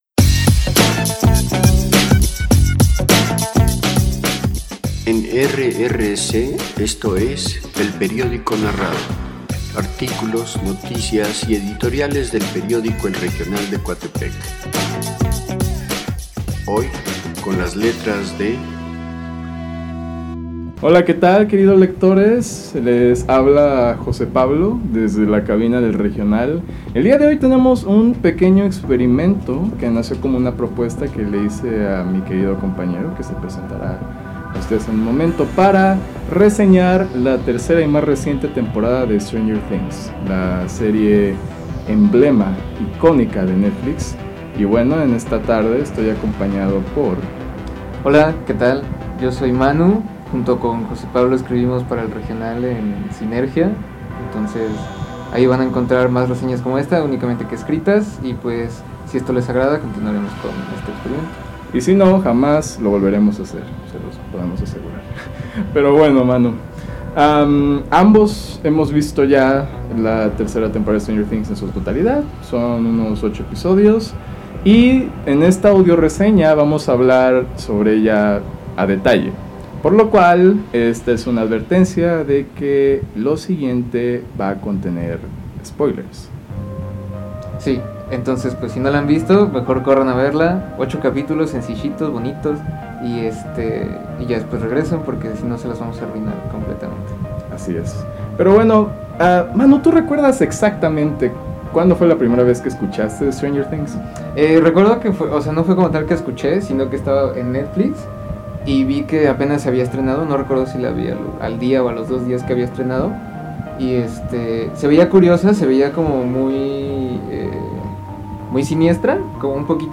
AUDIO-RESEÑA: STRANGER THINGS 3